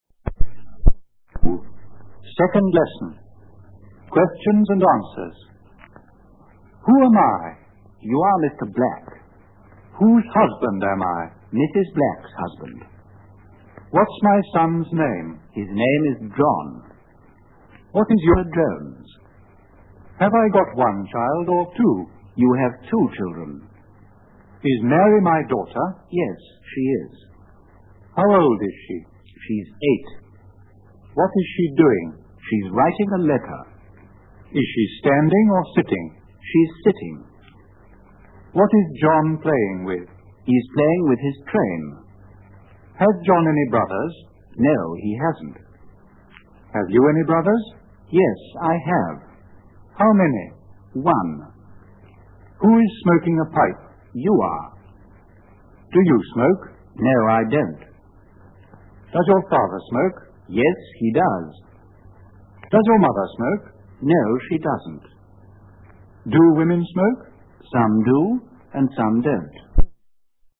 02 My family (Conversation)